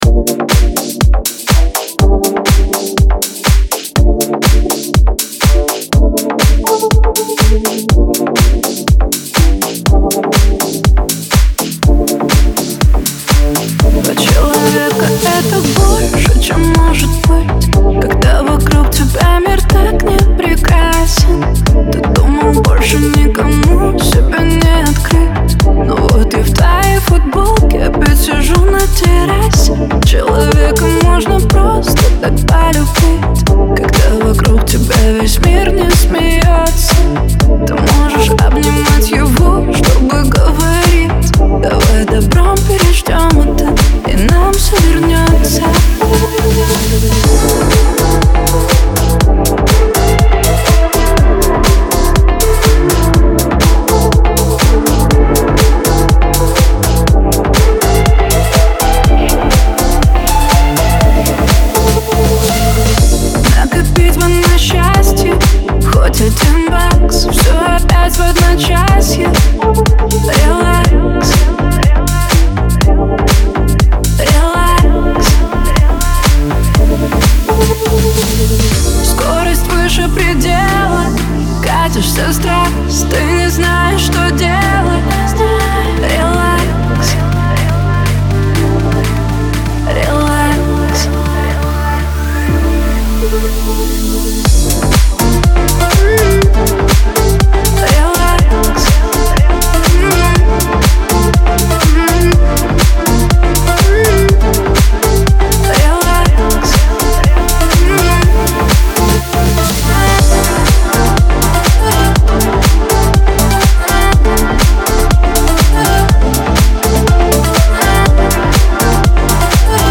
Трек размещён в разделе Клубная музыка | Ремиксы.